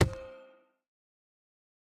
Minecraft Version Minecraft Version latest Latest Release | Latest Snapshot latest / assets / minecraft / sounds / block / chiseled_bookshelf / insert_enchanted2.ogg Compare With Compare With Latest Release | Latest Snapshot
insert_enchanted2.ogg